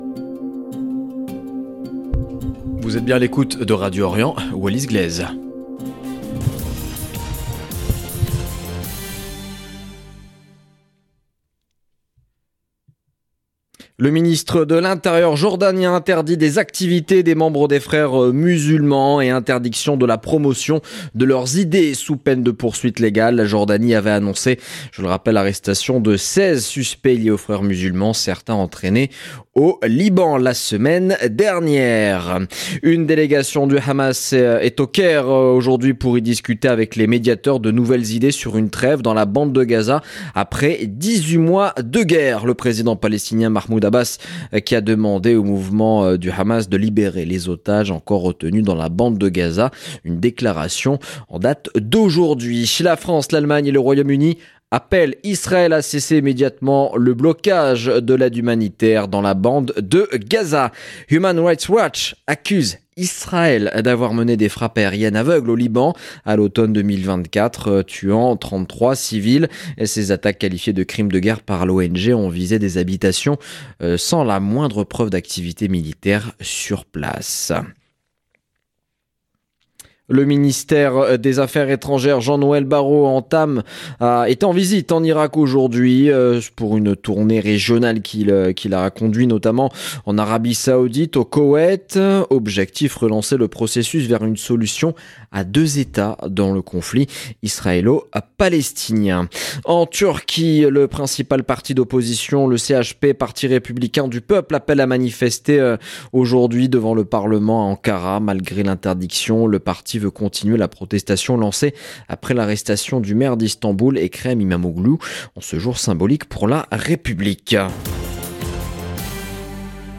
LE JOURNAL EN LANGUE FRANCAISE DU SOIR 23/04/2025